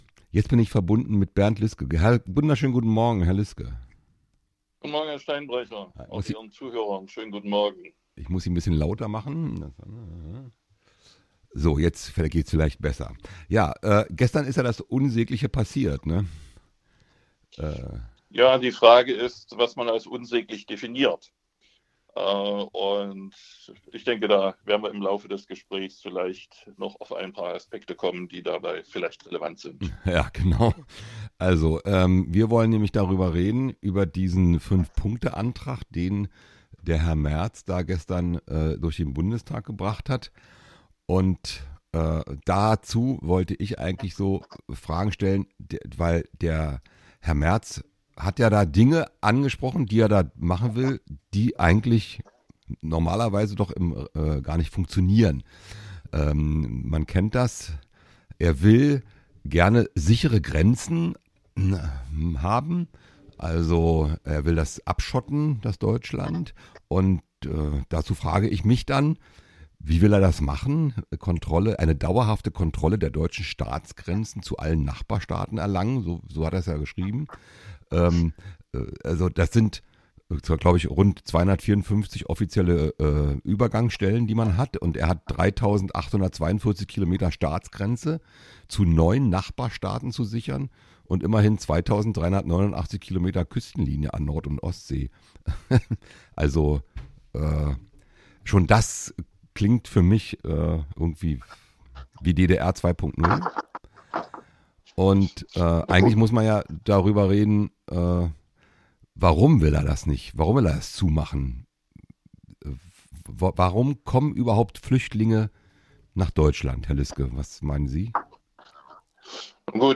Erster HINWEIS: Meine vielen äh´s darin und ein etwas langatmiger Sprech zeugen sicher davon, dass die vielleicht gerade noch haltbare These, ich könne das eine oder andere Wort zuweilen schon sinnvoll nebeneinander schreiben, nicht gleichbedeutend damit ist, es auch nacheinander fließend sprechen zu können.